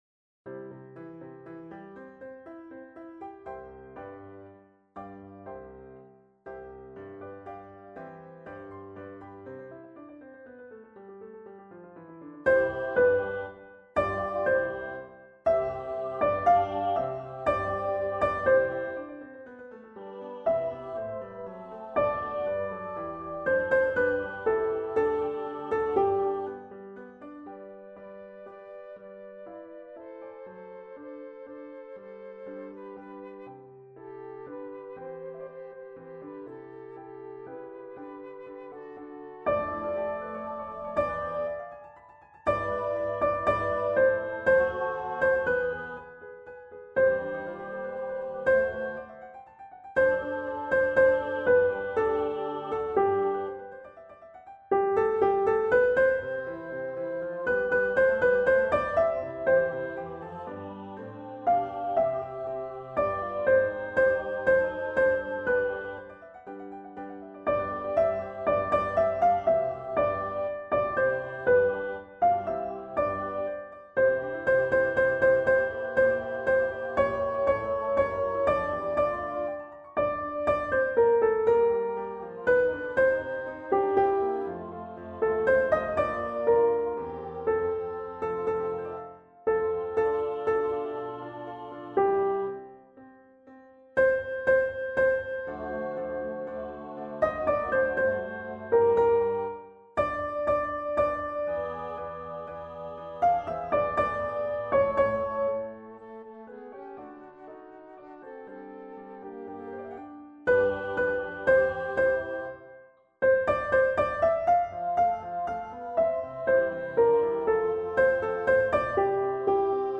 Mozart-Practice-Sop.mp3